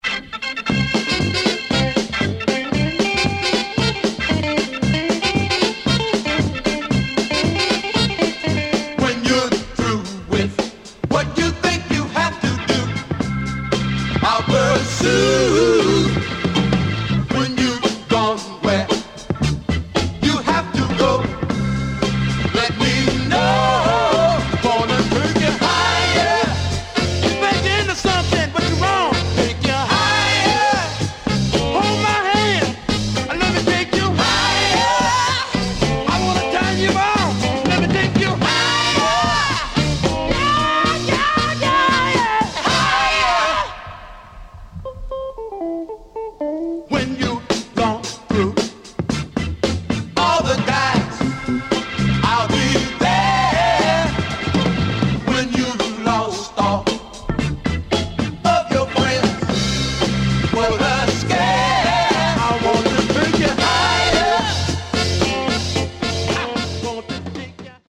Mono Single Master